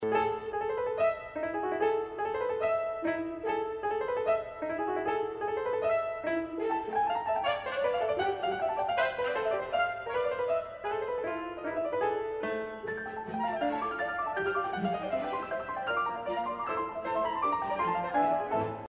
速度相同，在圆号、长号伴奏下，钢琴表现主题